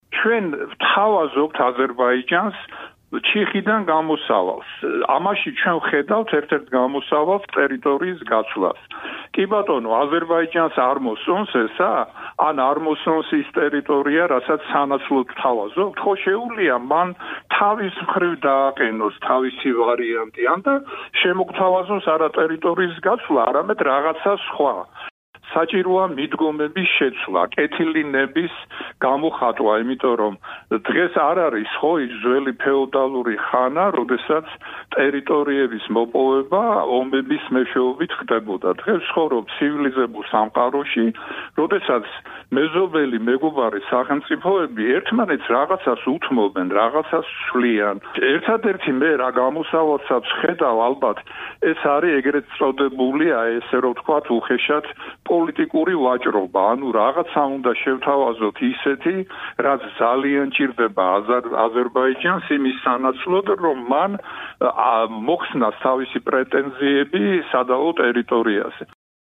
ისტორიკოსი საუბრობს დავით გარეჯის სამონასტრო კომპლექსთან დაკავშირებულ პრობლემებზე